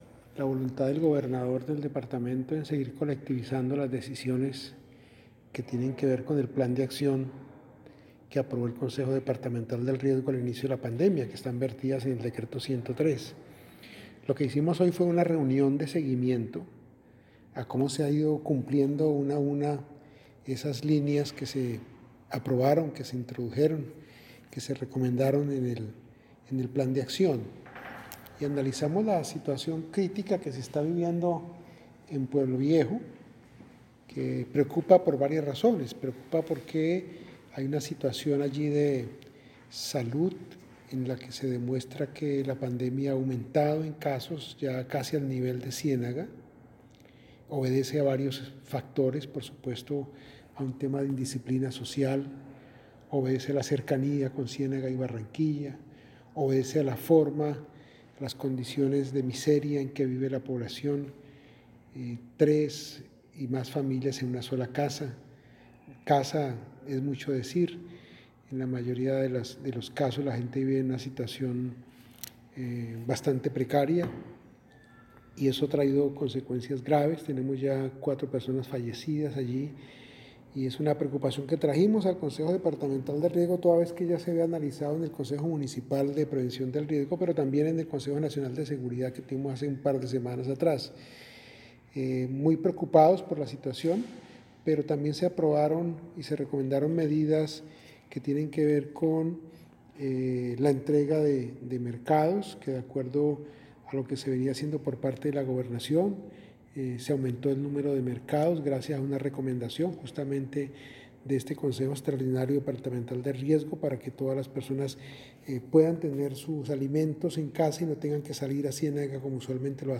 AUDIO-JOSE-HUMBERTO-TORRES-CONCLUSIONES-CONSEJO-DEPARTAMENTAL-DE-GESTIÓN-DEL-RIESGO-online-audio-converter.com_.mp3